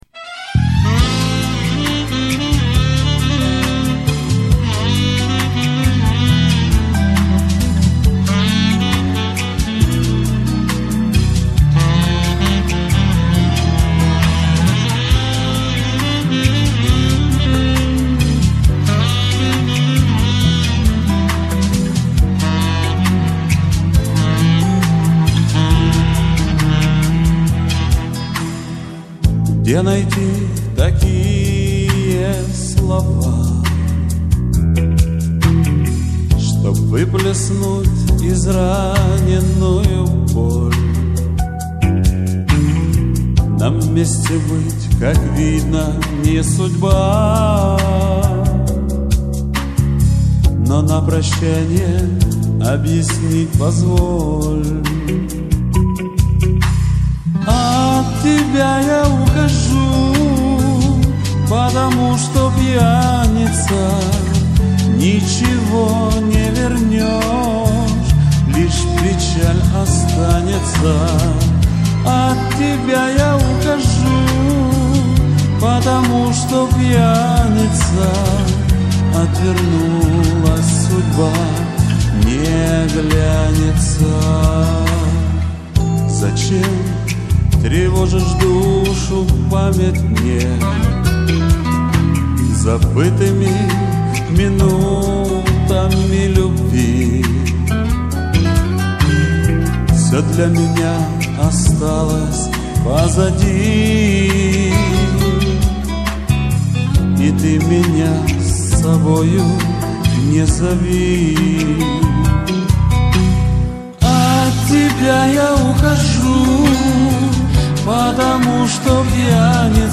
Ни о каком качестве речи и не было.